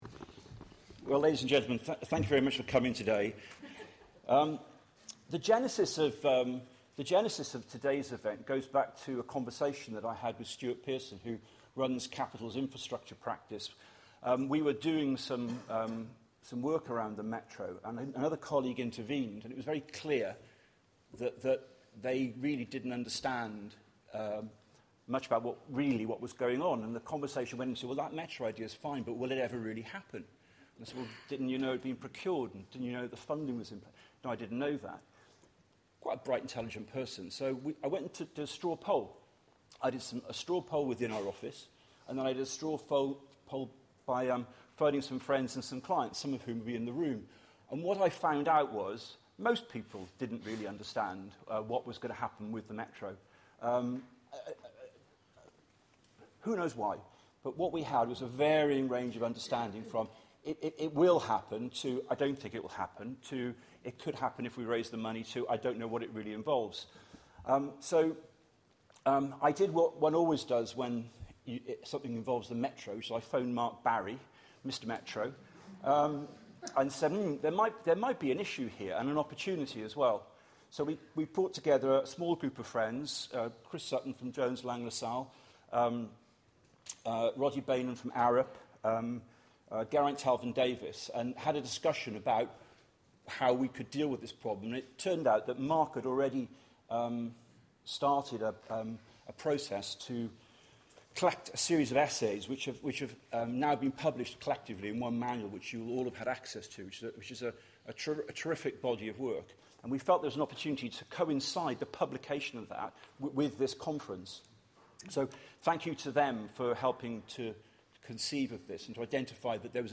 This is the audio recording from the Metro & Me event morning session, which was held at Radisson Blu hotel in Cardiff on 8th October 2018.